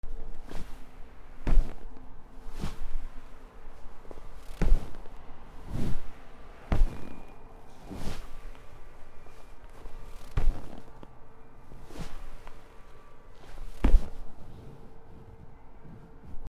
硬めの椅子
/ J｜フォーリー(布ずれ・動作) / J-22 ｜椅子